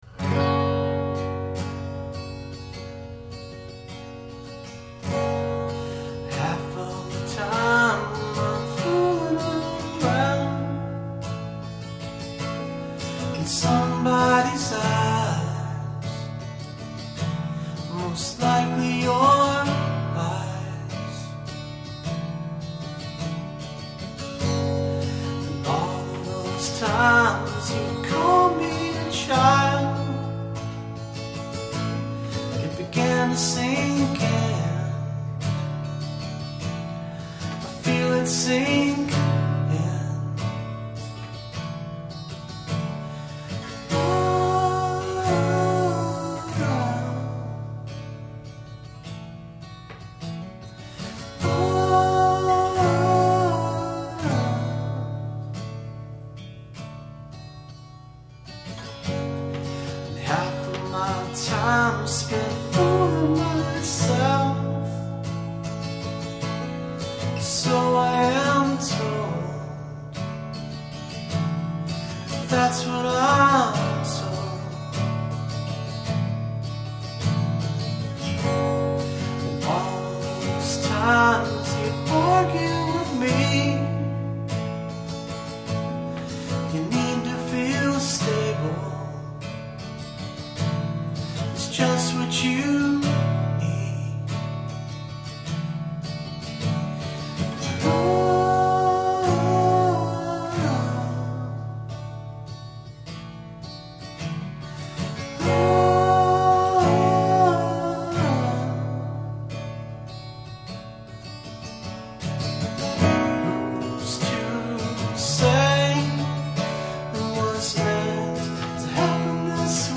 Here's a demo that I recorded on my Tascam 424 mkIII. It's just one track with vocals and acoustic both into one mic (SP C1), but I think it came out fairly nice.